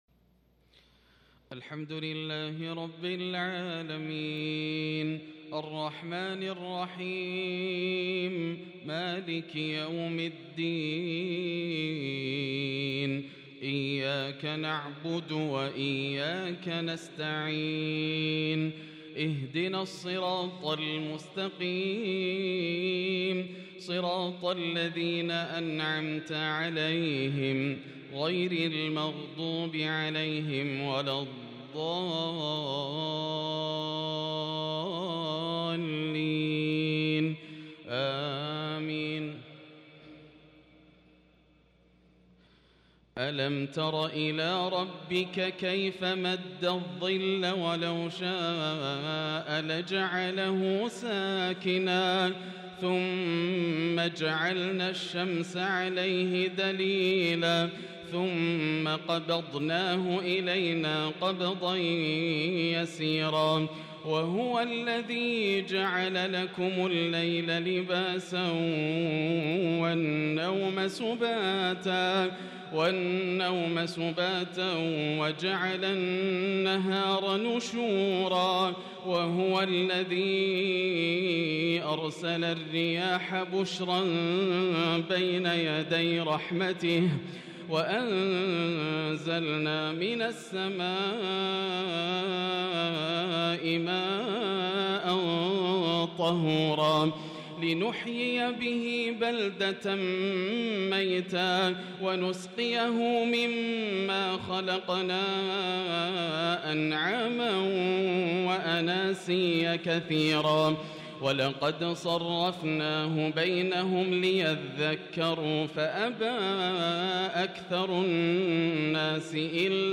عشاء السبت 7-5-1443هـ من سورة الفرقان | Isha prayer from Surah Al-Furqān 11/12/2021 > 1443 🕋 > الفروض - تلاوات الحرمين